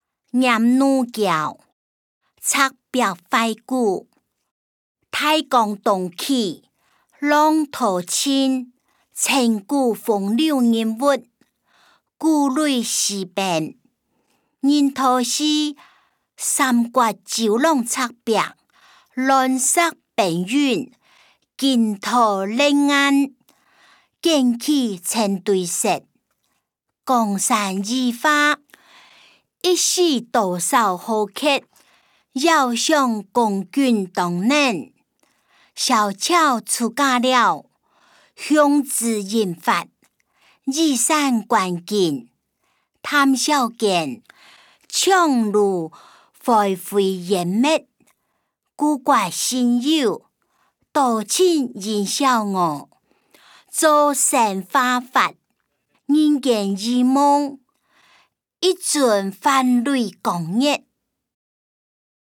詞、曲-念奴嬌•赤壁懷古音檔(饒平腔)